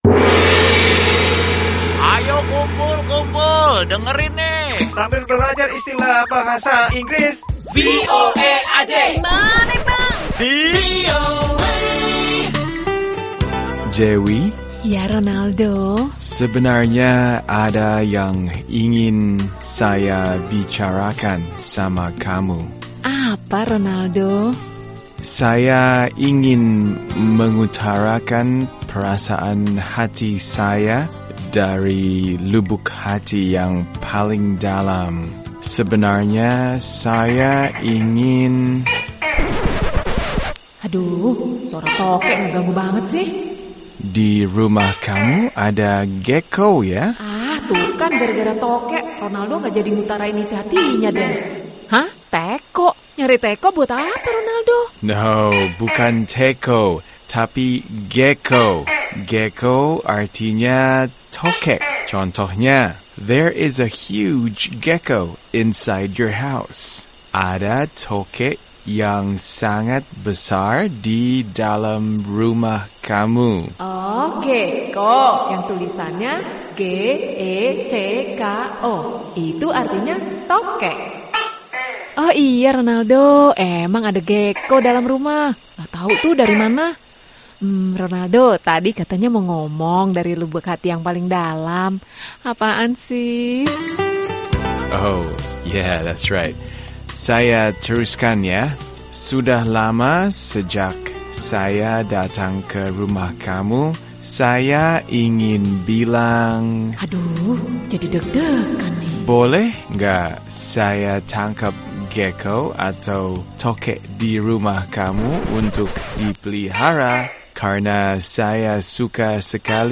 Kali ini ada kata Gecko yang artinya Tokek. Simak cara pengucapan dan penggunaannya dalam kalimat sehari-hari dalam percakapan yang lucu ini.